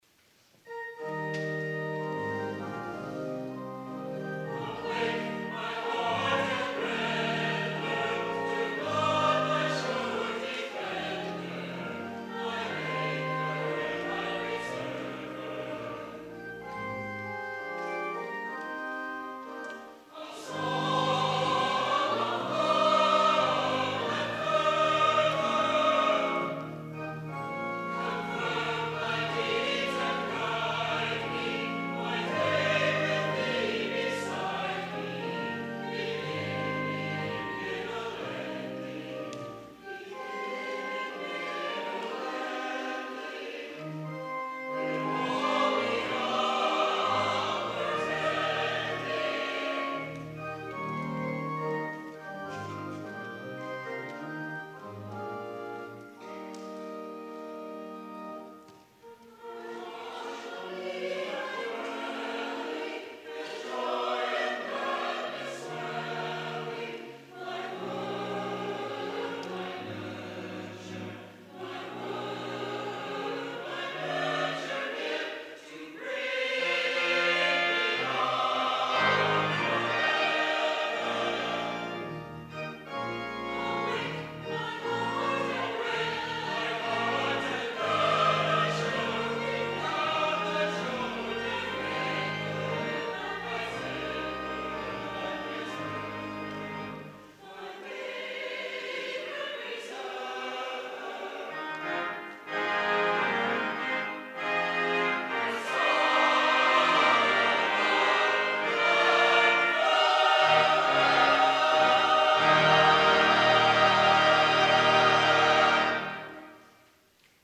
organ
Chancel Choir
guest organist